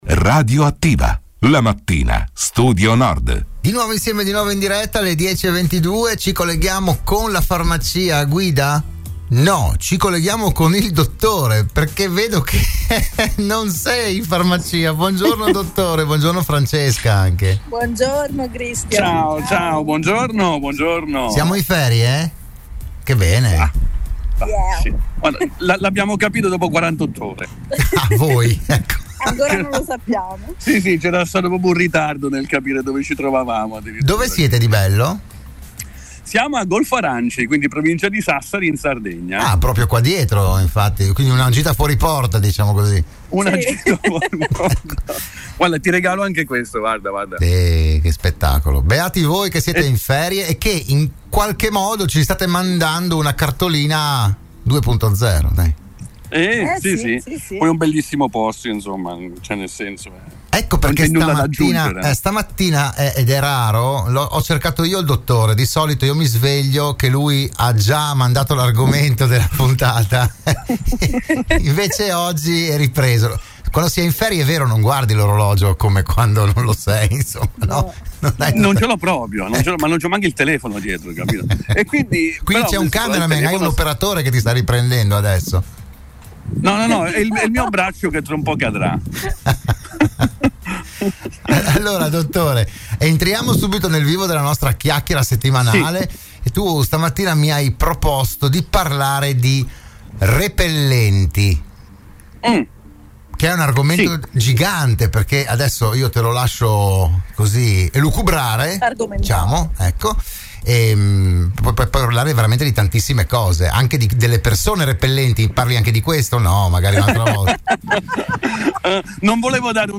Nuova puntata per “Buongiorno Dottore”, il programma di prevenzione e medicina in onda ogni lunedì alle 10.20 all’interno della trasmissione di Radio Studio Nord “RadioAttiva”.